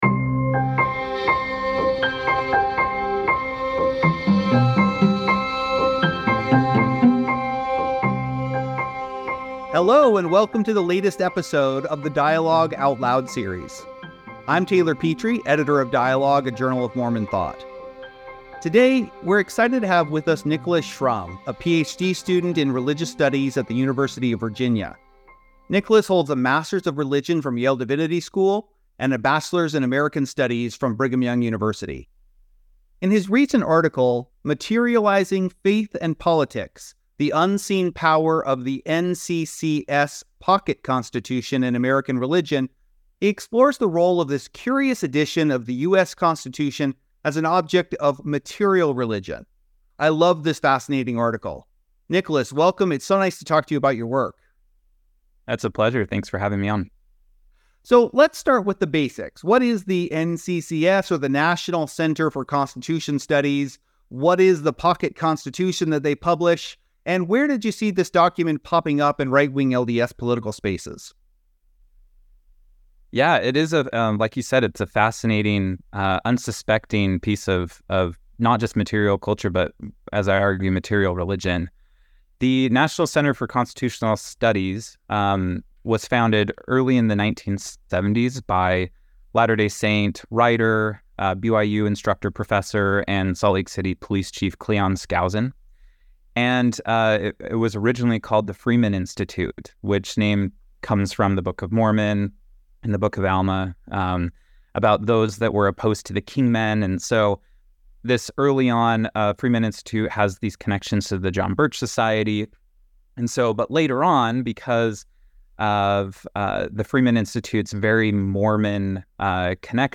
Faith and Politics: A Conversation